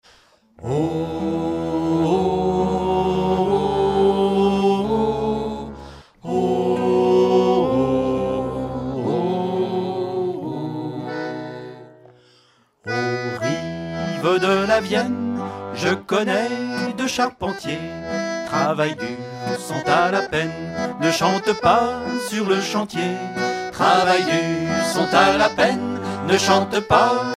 Genre strophique
Concert donné en 2004
Pièce musicale inédite